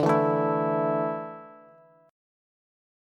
Dsus2 chord